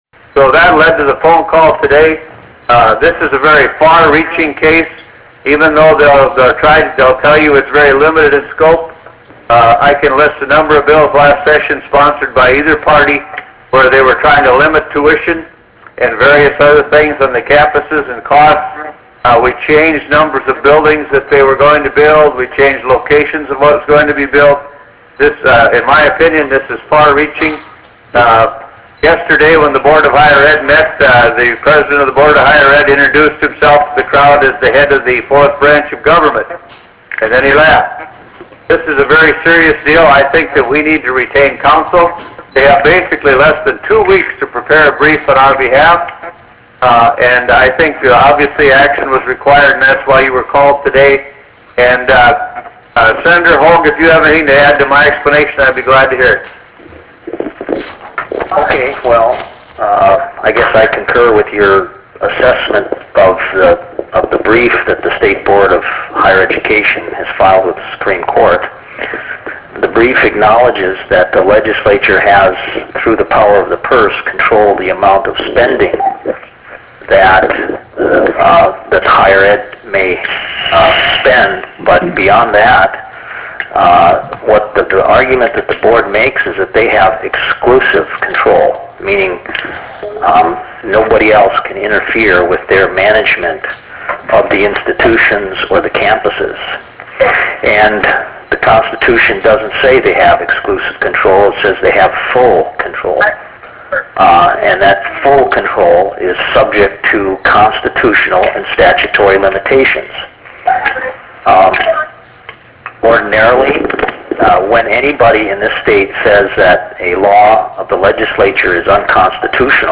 This meeting was conducted by teleconference call connection.
Prairie Room State Capitol Bismarck, ND United States